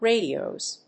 /ˈrediˌoz(米国英語), ˈreɪdi:ˌəʊz(英国英語)/